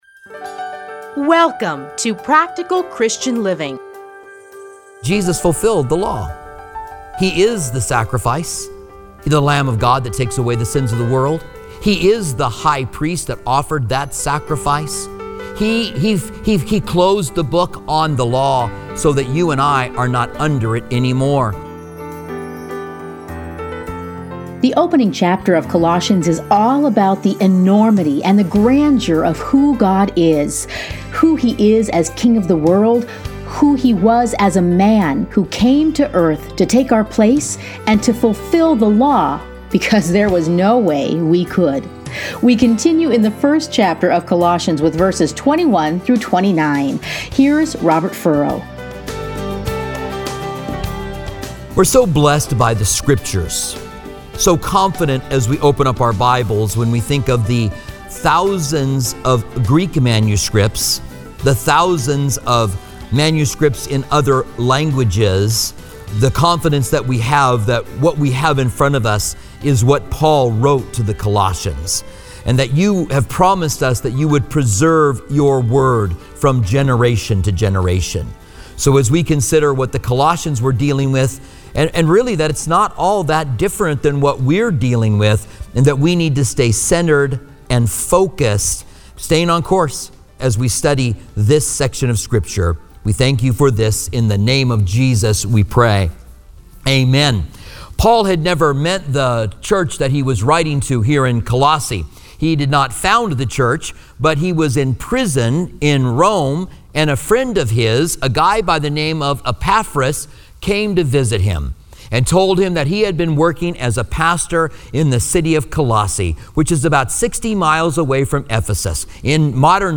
Listen here to a teaching from Colossians.